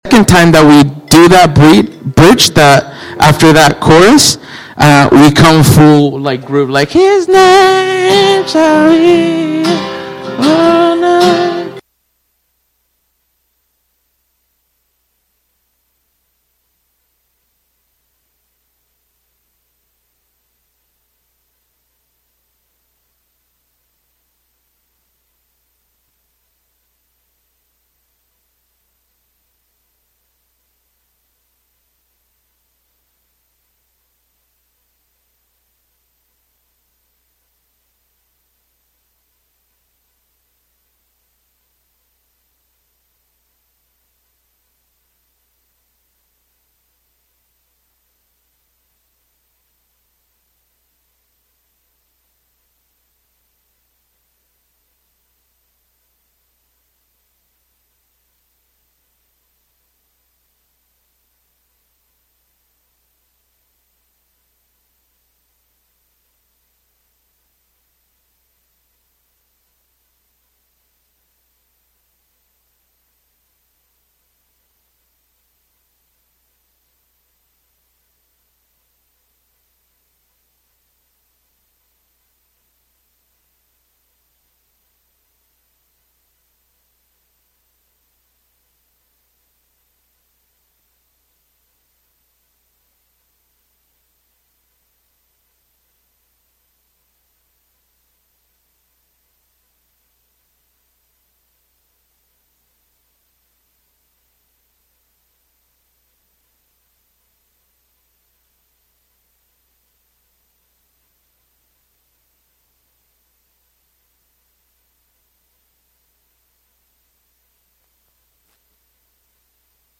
Sermons by Redemption City Church